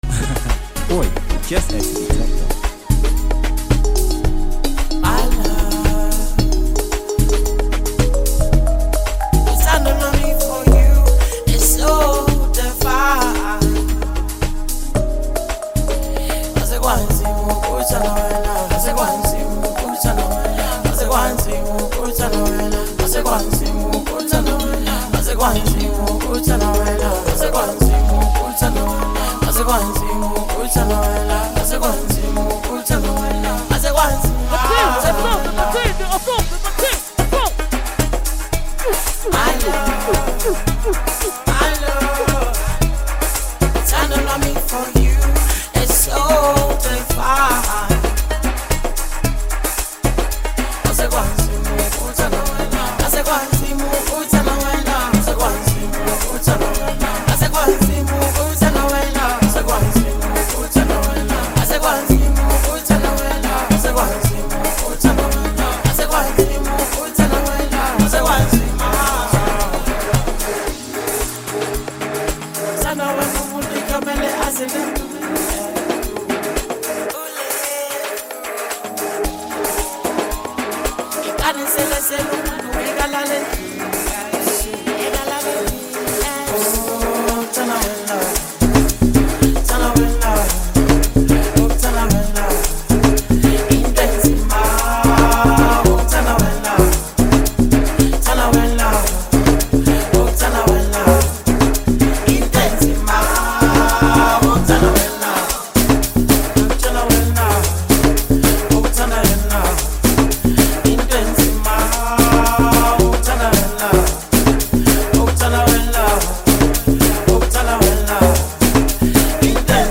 mixtape
Amapiano
If you’re into soulful music, you’re gonna love this one.